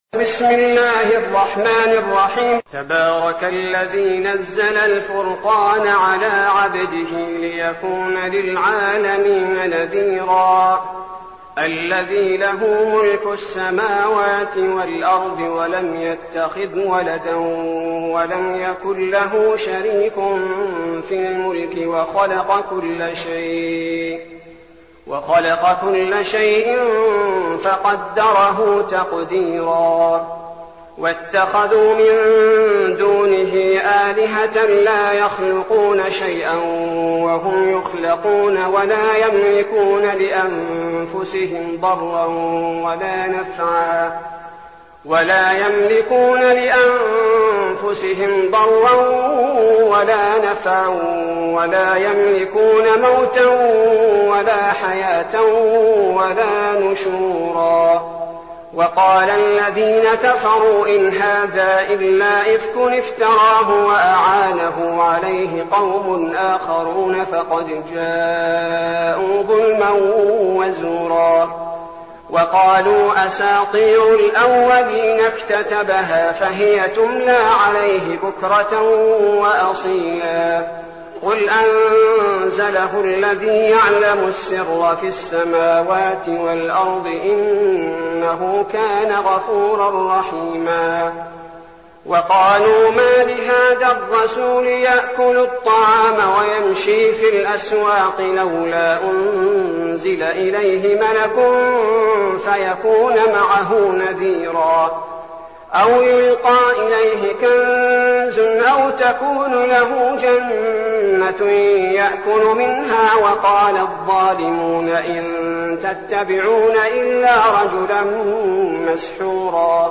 المكان: المسجد النبوي الشيخ: فضيلة الشيخ عبدالباري الثبيتي فضيلة الشيخ عبدالباري الثبيتي الفرقان